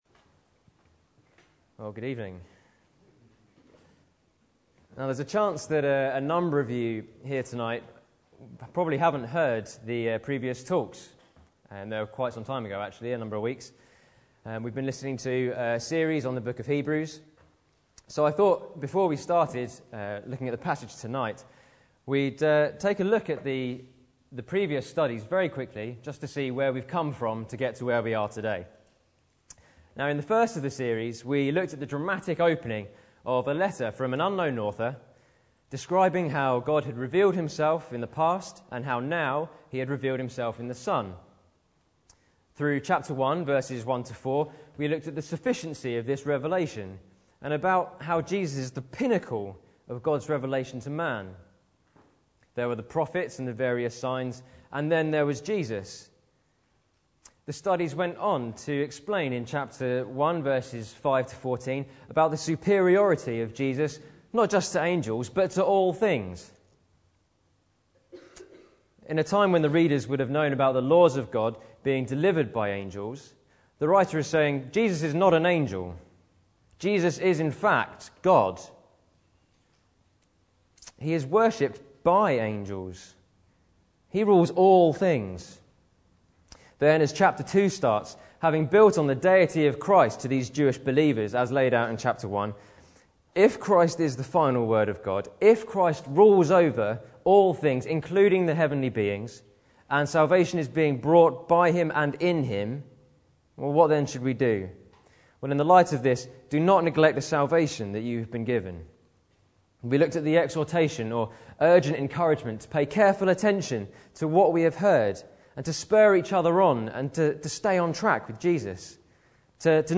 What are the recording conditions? An independent evangelical church